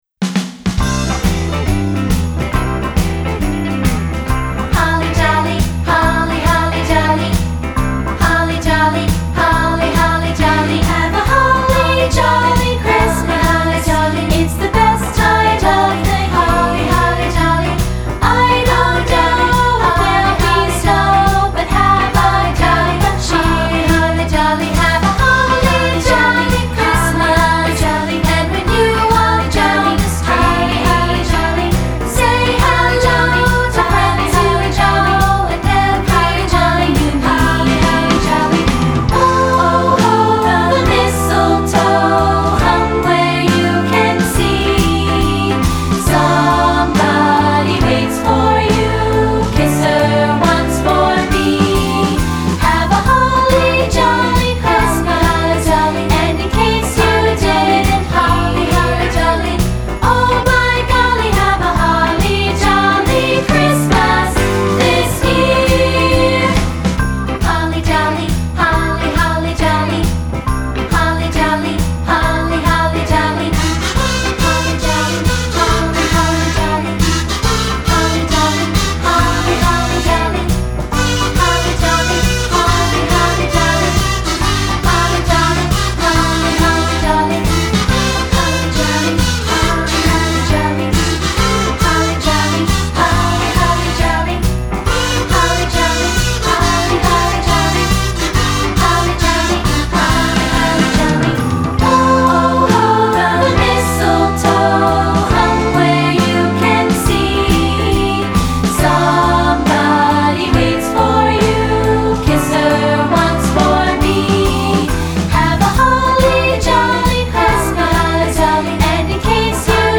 Recueil pour Chant/vocal/choeur - 2 Parties Mixtes